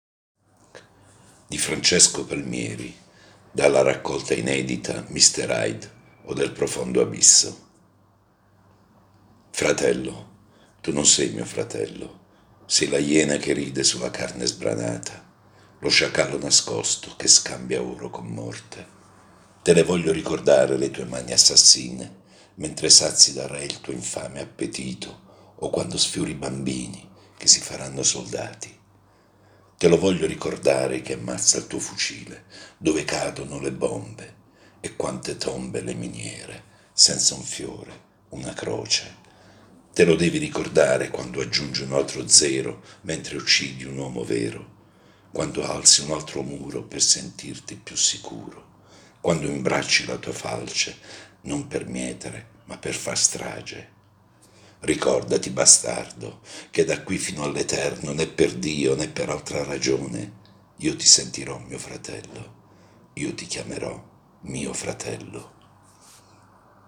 Solo un’avvertenza: la voce narrante è quella di un lettore comune e non l’espressione professionale di un attore, così come l’ambiente operativo che non è uno studio di registrazione.